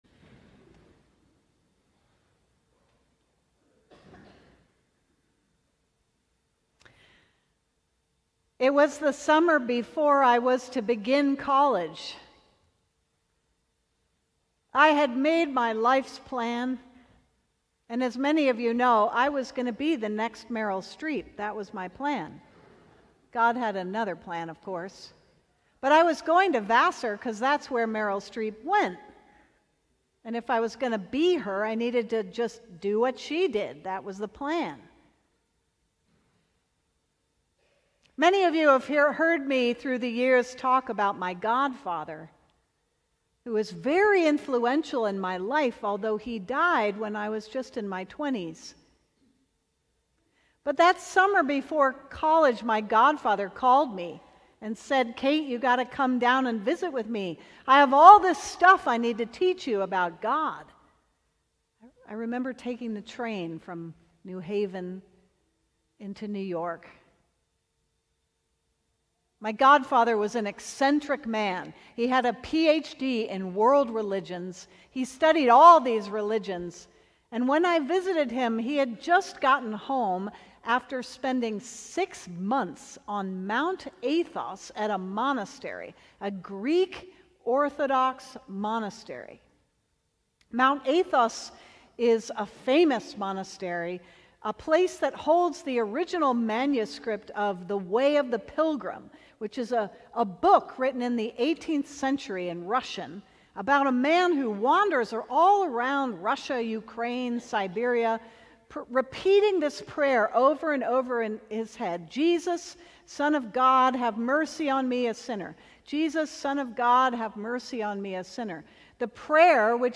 Sermon: Speaking of Sin - St. John's Cathedral